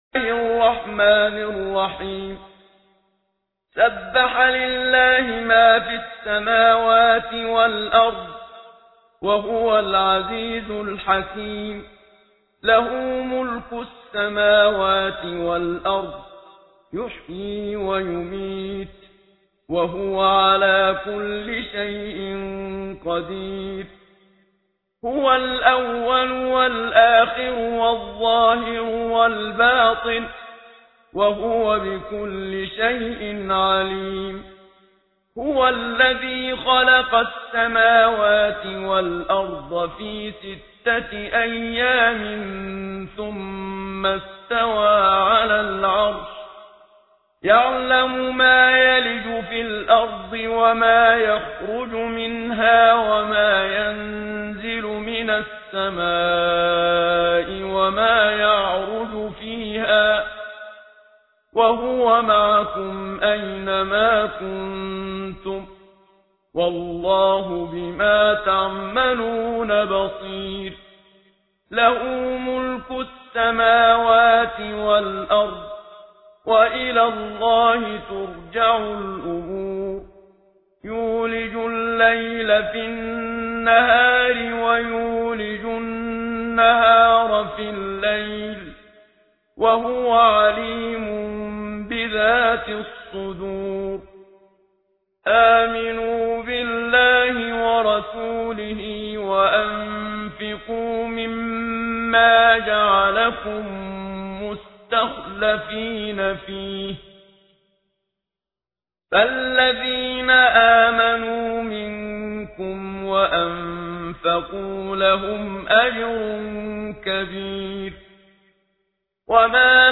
سوره ای از قرآن کریم که شما را از آتش جهنم حفظ می کند + متن و ترتیل استاد منشاوی